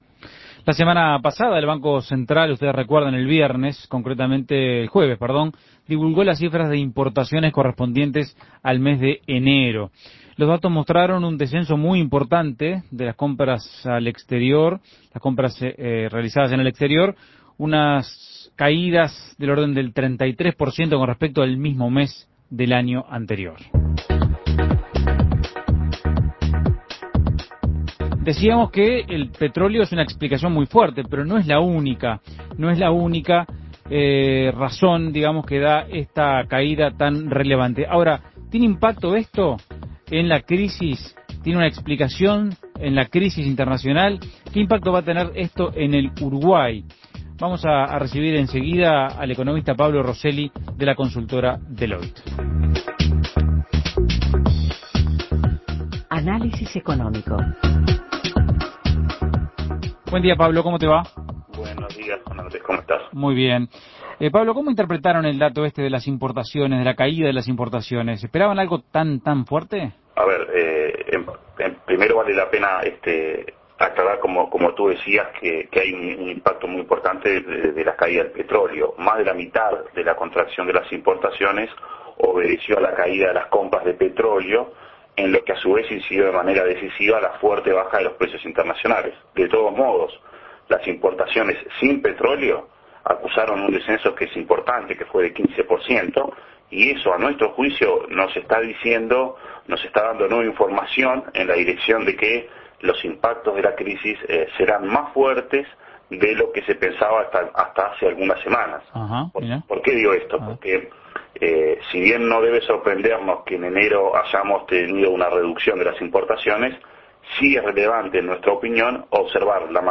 Análisis Económico ¿Cómo se interpreta la caída que mostraron las importaciones en enero?